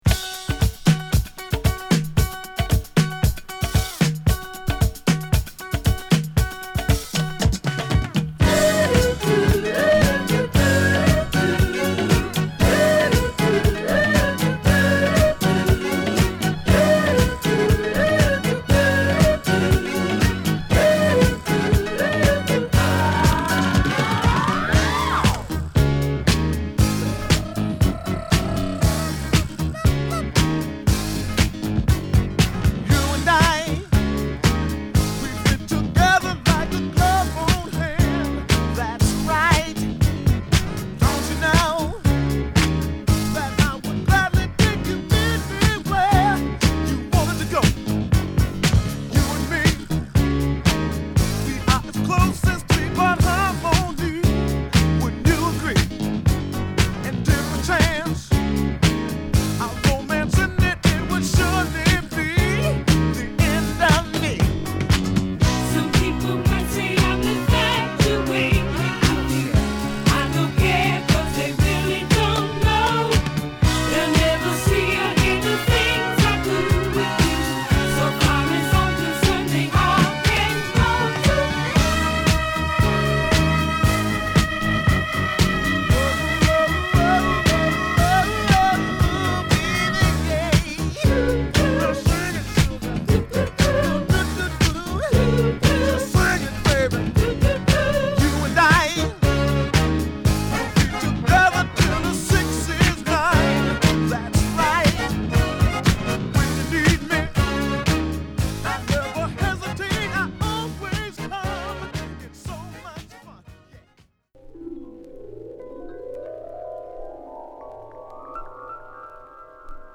女性コーラスを絡めたナイスなファンクブギー